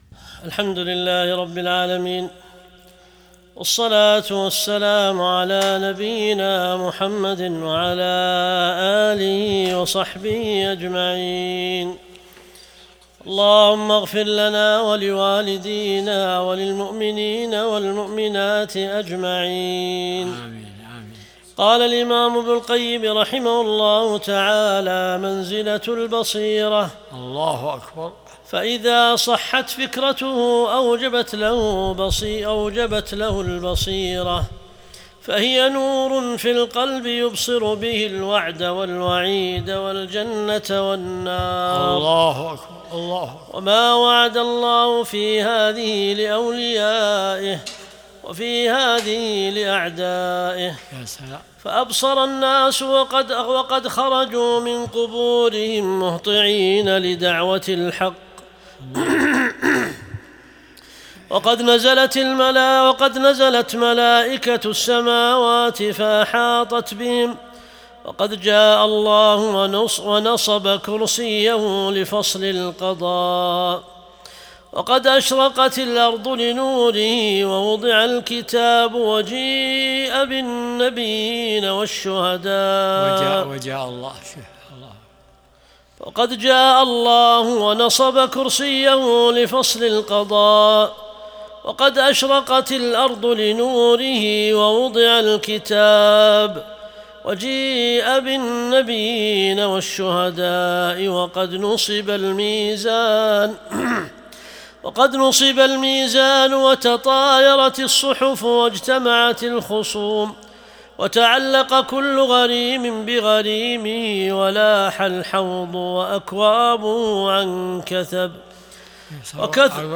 درس الأربعاء 76